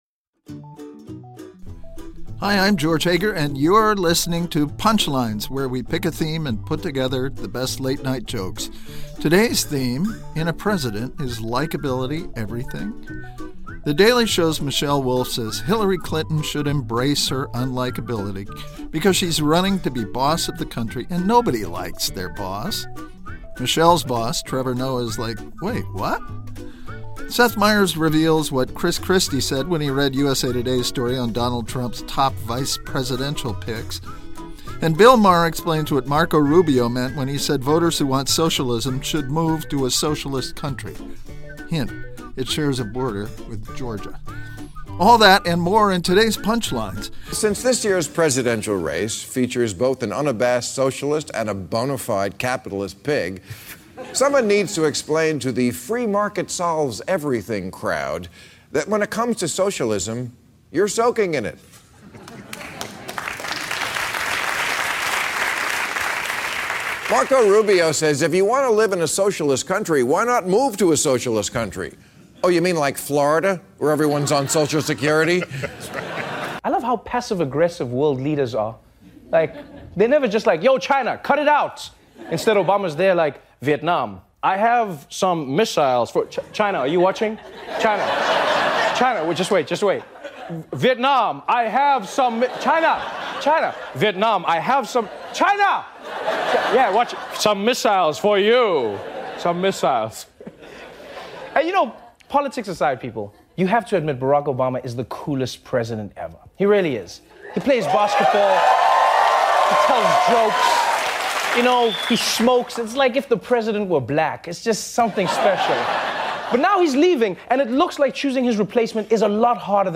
The late-night comics on a poll that puts Clinton slightly behind, socialism and emails.